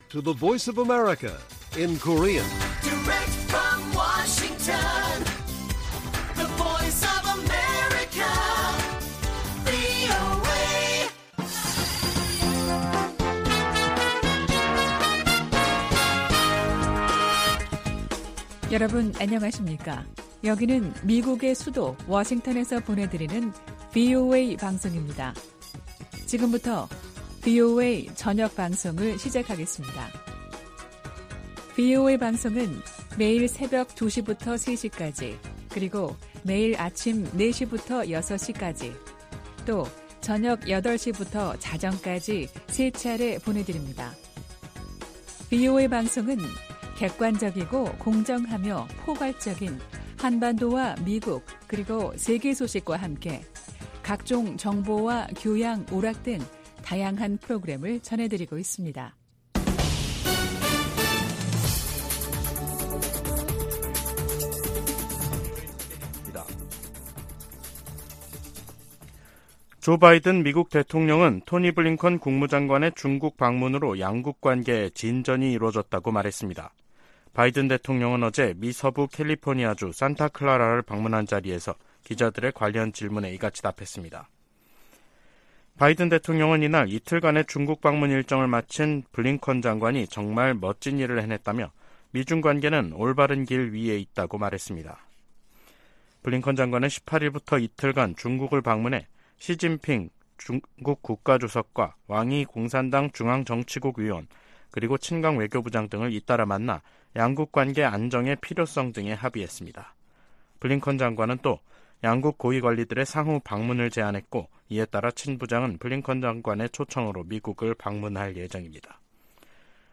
VOA 한국어 간판 뉴스 프로그램 '뉴스 투데이', 2023년 6월 20일 1부 방송입니다. 베이징을 방문한 블링컨 미 국무장관은 중국에 북한이 도발을 멈추고 대화 테이블로 나오도록 영향력을 행사해 줄 것을 촉구했습니다. 미 국방부 콜린 칼 차관의 최근 일본 방문은 미일 동맹의 진전을 보여주는 것이었다고 국방부가 평가했습니다. 한국군은 대북 무인기 작전 등을 주요 임무로 하는 드론작전사령부를 9월 창설할 예정입니다.